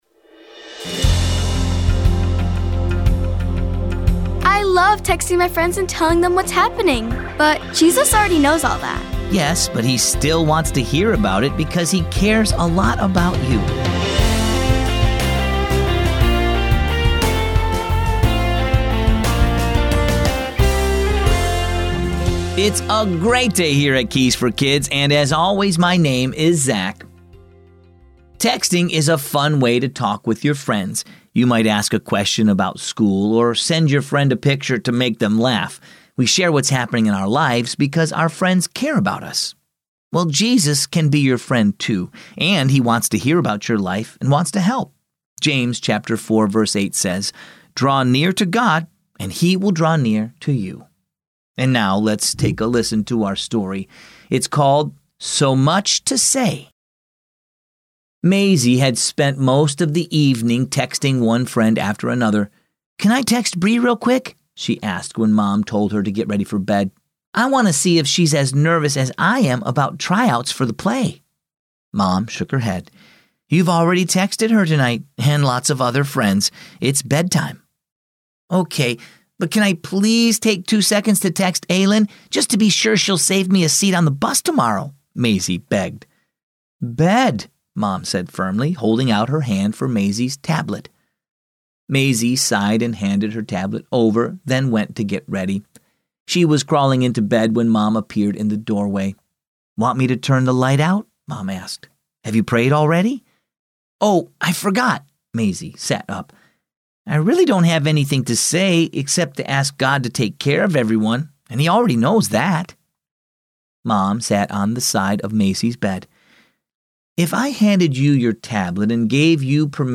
Keys for Kids is a daily storytelling show based on the Keys for Kids children's devotional.